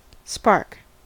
spark: Wikimedia Commons US English Pronunciations
En-us-spark.WAV